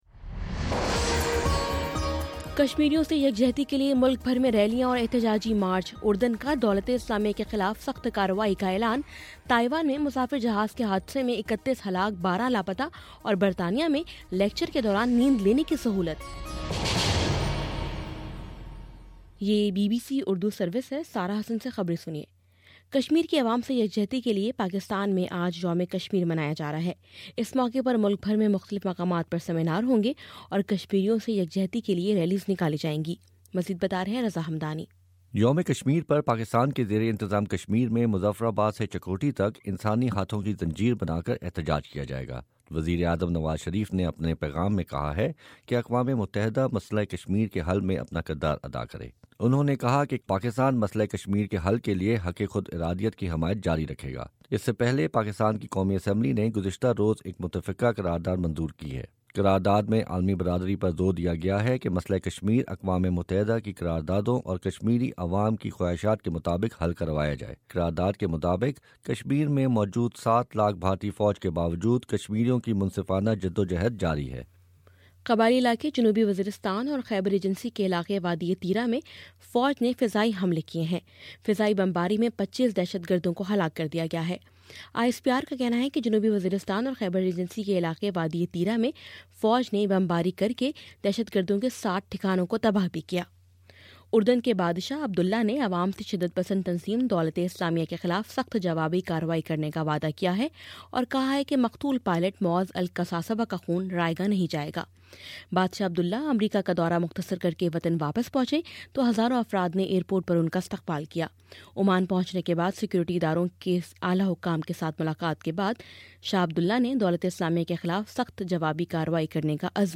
فروری 05: صبح نو بجے کا نیوز بُلیٹن